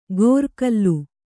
♪ gōrkallu